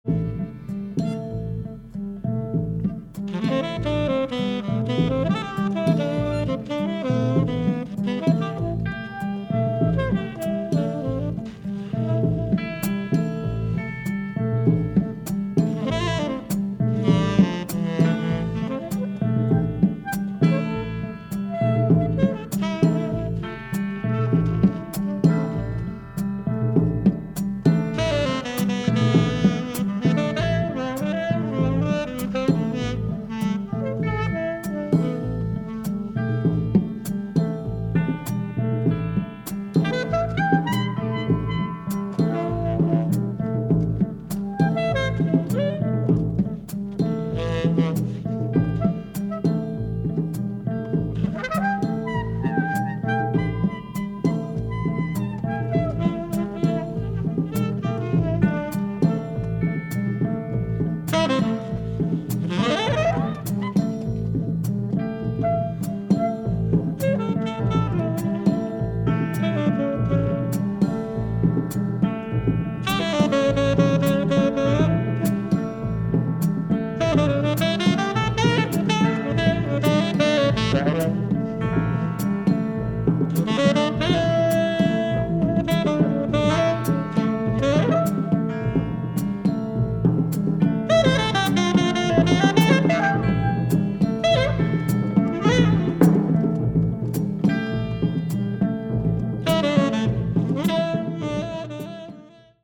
Superb Hungarian jazz session
Very deep, floating, exotic jazz.